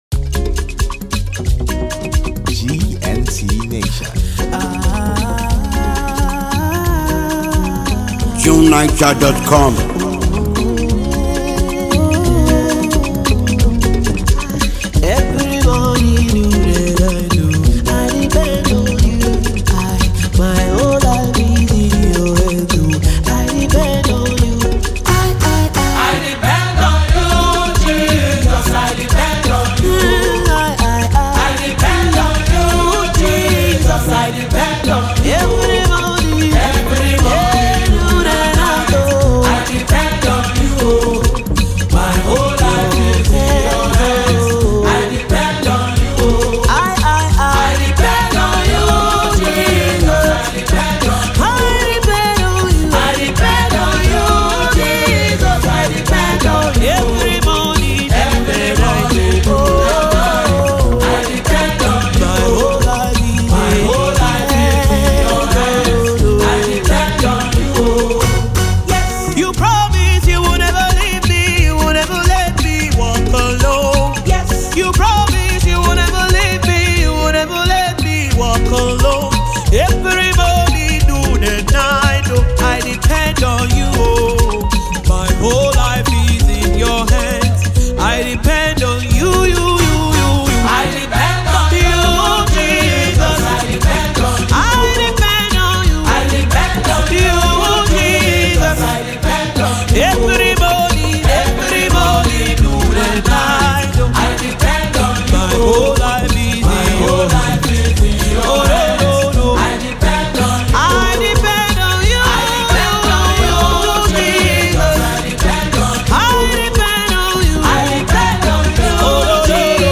a phenomenally gifted Nigerian gospel singer
Anyone looking for soul-stirring music should have it.